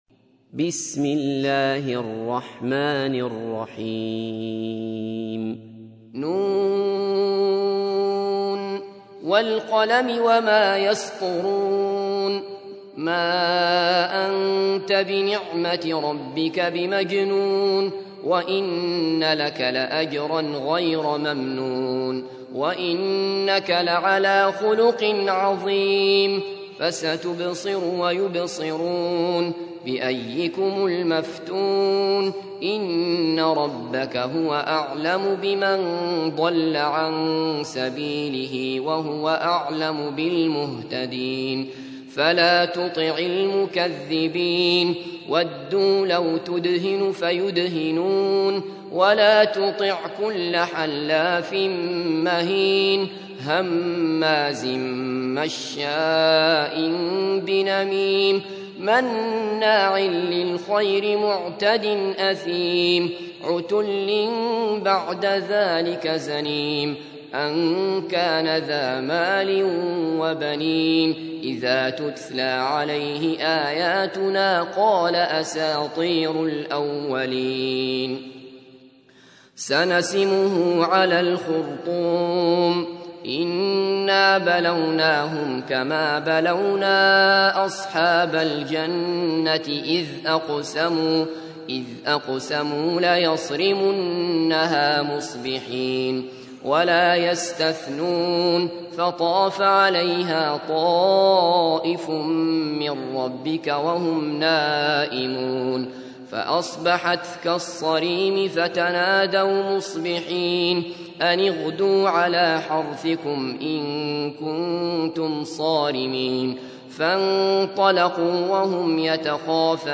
تحميل : 68. سورة القلم / القارئ عبد الله بصفر / القرآن الكريم / موقع يا حسين